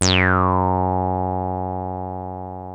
303 F#2 4.wav